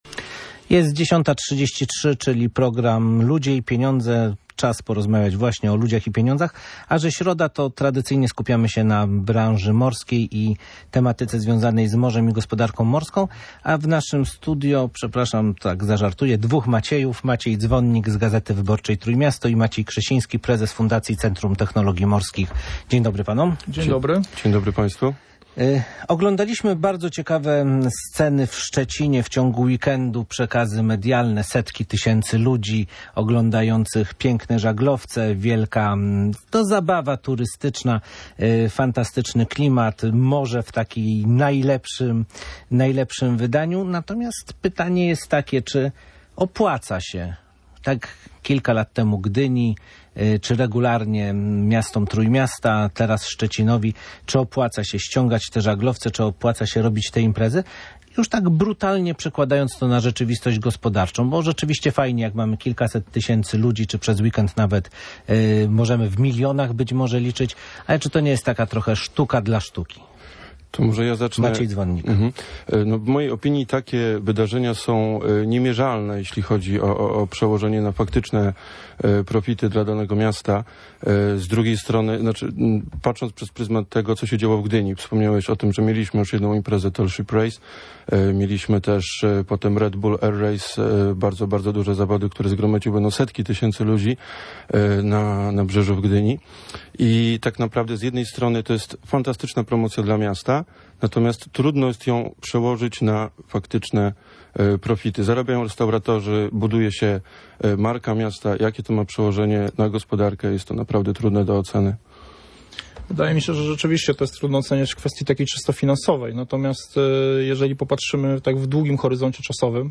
Czy wydatki związane z organizacją tak dużych imprez jak finał regat The Tall Ships Races w Szczecinie, które spadają na barki miasta nie odbiją się negatywnie na innych miejskich inwestycjach? Dyskutowali o tym goście Radia Gdańsk.